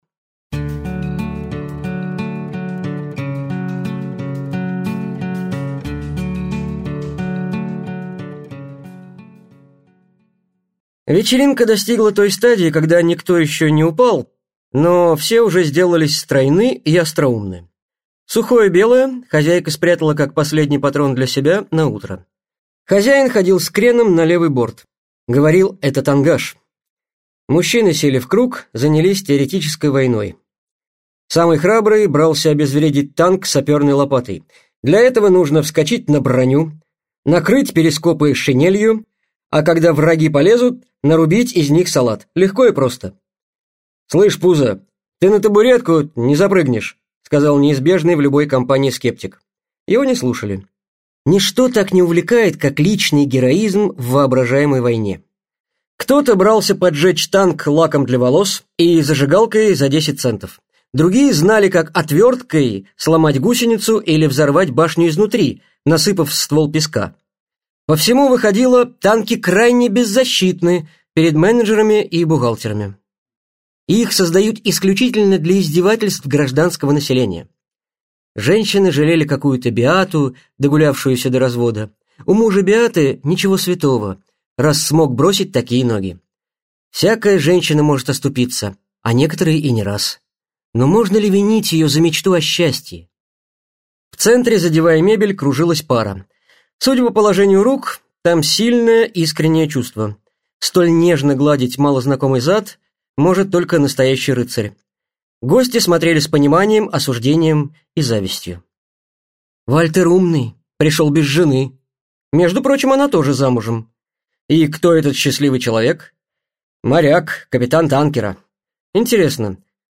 Аудиокнига Когда утонет черепаха - купить, скачать и слушать онлайн | КнигоПоиск